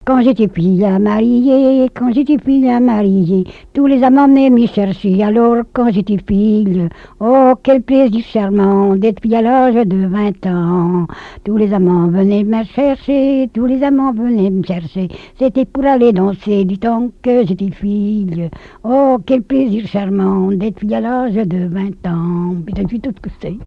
danse : ronde
Genre laisse
collecte en Vendée
répertoire de chansons, et d'airs à danser
Pièce musicale inédite